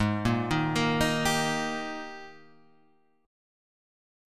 Eb/Ab Chord